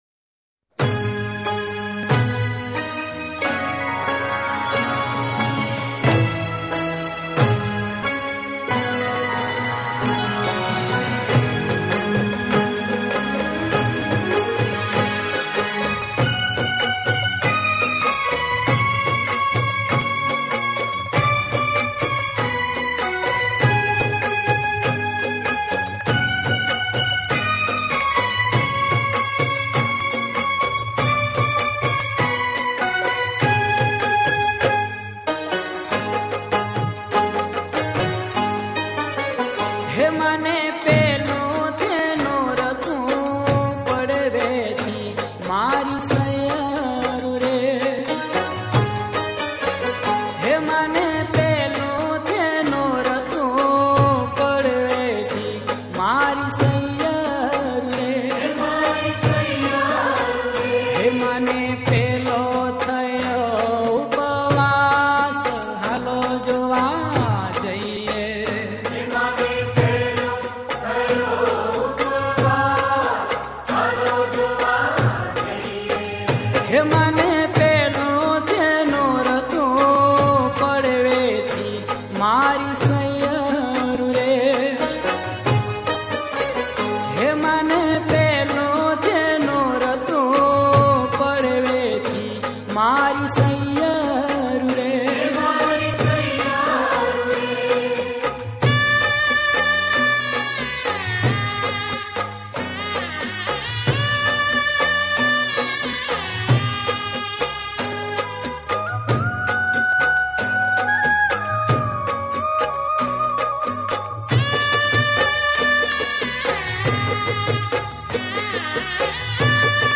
Non Stop Dandiya Mix